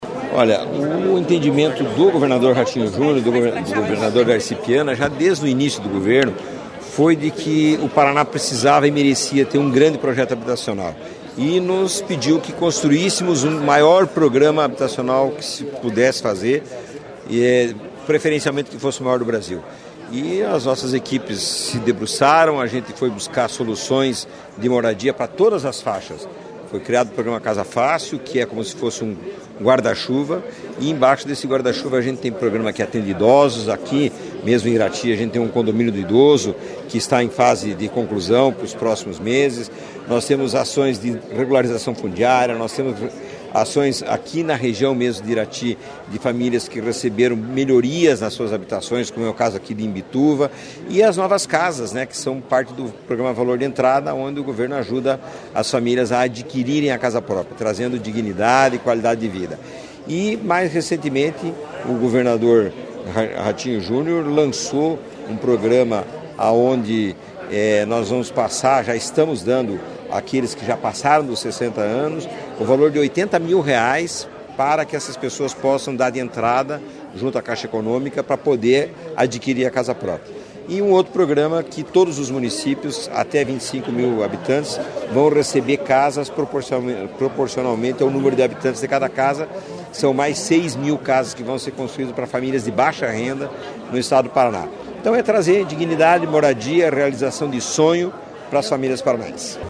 Sonora do presidente da Cohapar, Jorge Lange, sobre a entrega de casas próprias a 46 famílias de Irati